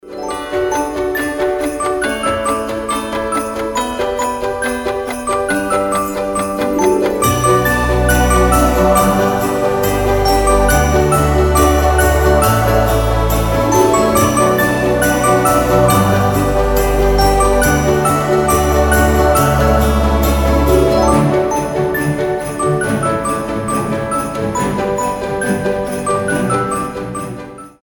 • Качество: 320, Stereo
без слов
инструментальные
добрые
колокольчики
хорошее настроение
рождественские
дружелюбные